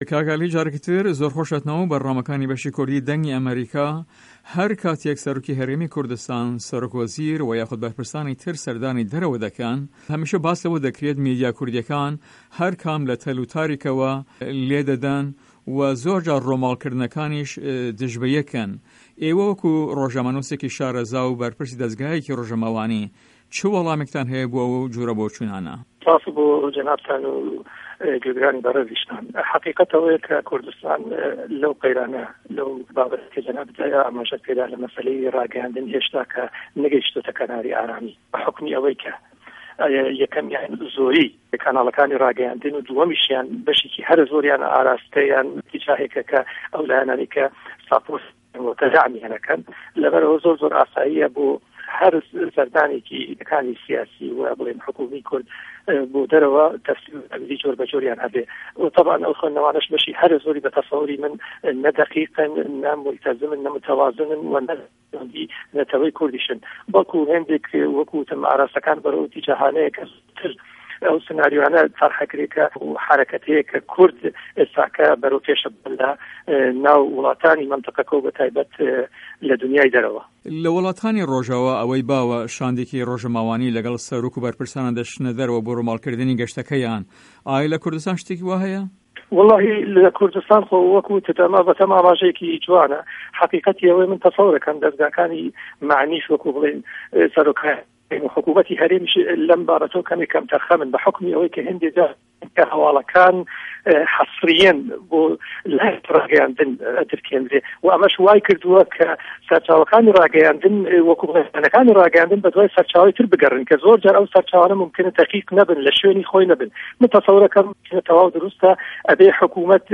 هه‌رێمه‌ کوردیـیه‌کان - گفتوگۆکان
عه‌لی فه‌یلی ئه‌ندامی په‌رله‌مانی کوردستان